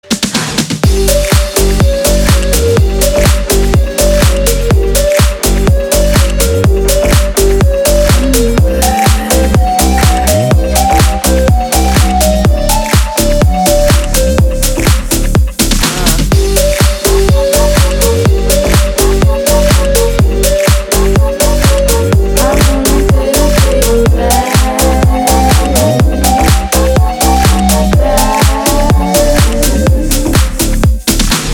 • Качество: 320, Stereo
remix
атмосферные
женский голос
Electronic
Club House
Dance Pop